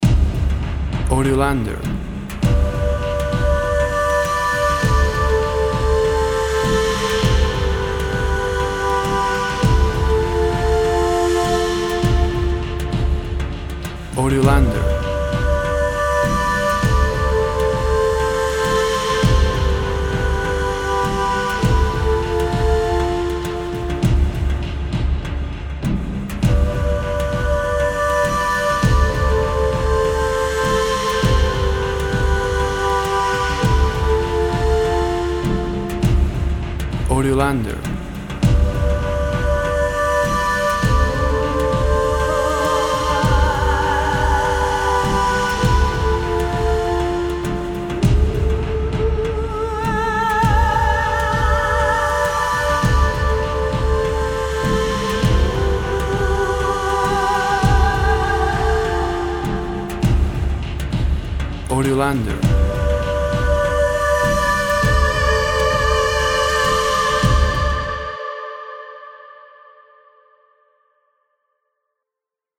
V2 Deep female vocals accompanied by epic percussion.
WAV Sample Rate 16-Bit Stereo, 44.1 kHz
Tempo (BPM) 100